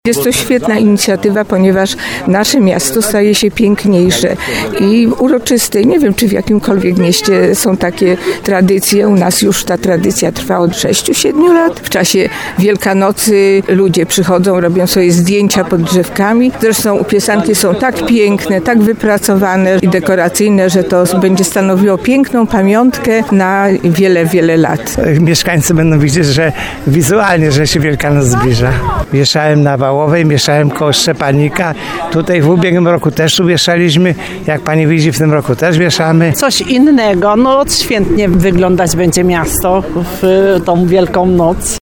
W rozmowie z Radiem RDN Małopolska osoby zaangażowane w ozdabianie drzew kolorowymi pisankami, chwalą tą inicjatywę.